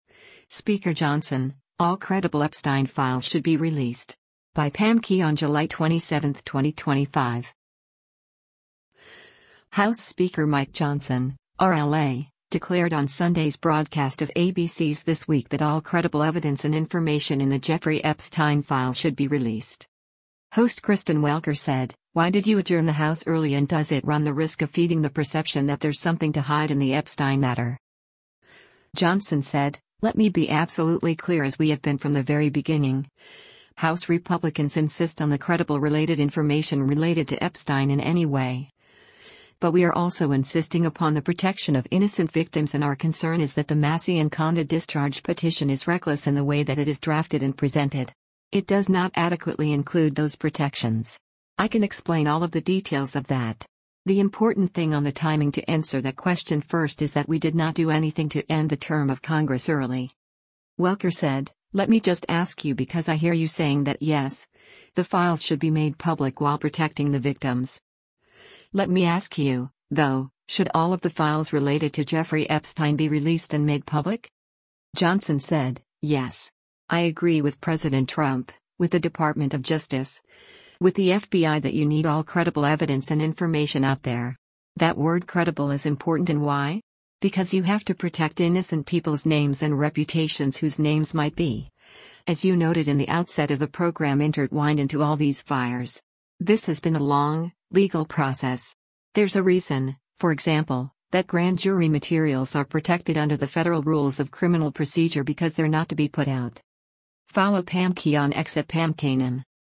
House Speaker Mike Johnson (R-LA) declared on Sunday’s broadcast of ABC’s “This Week” that all “credible evidence and information ” in the Jeffrey Epstein files should be released.